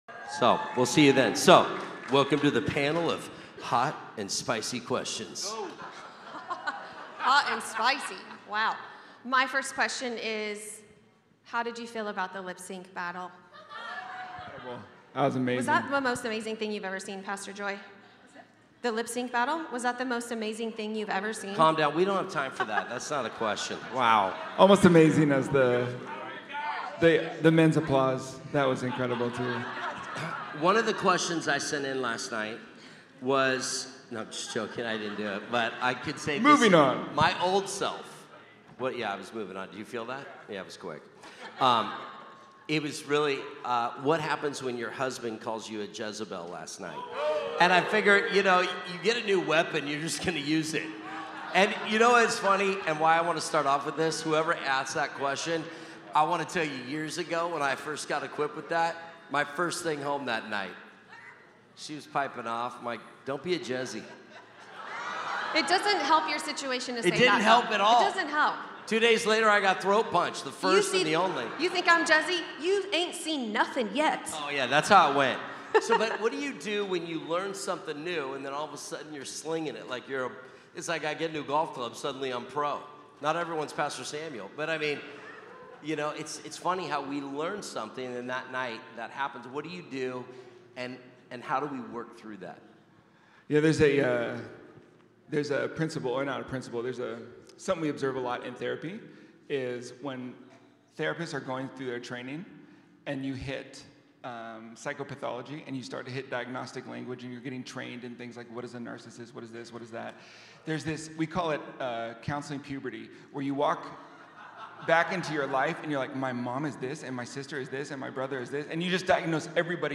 Recorded at Awaken Church, CA